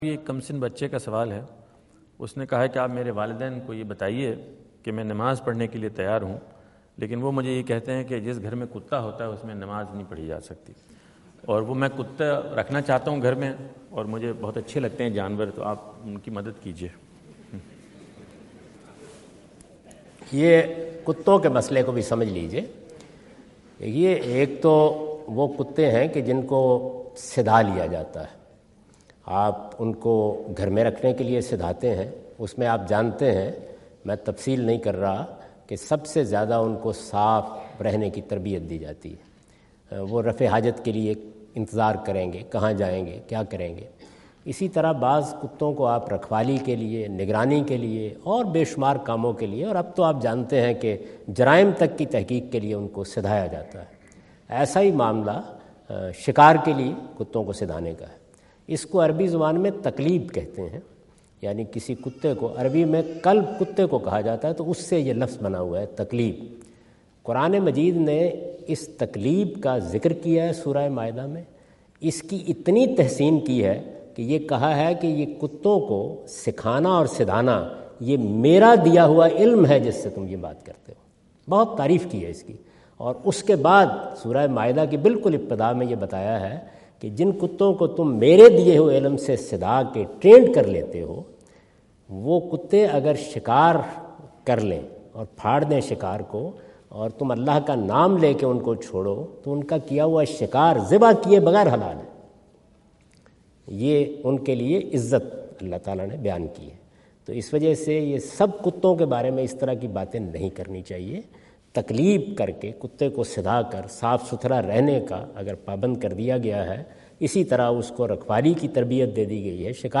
Javed Ahmad Ghamidi answer the question about "Keeping a Dog as a Pet" asked at North Brunswick High School, New Jersey on September 29,2017.
جاوید احمد غامدی اپنے دورہ امریکہ 2017 کے دوران نیوجرسی میں "گھر میں پالتوکتا رکھنا" سے متعلق ایک سوال کا جواب دے رہے ہیں۔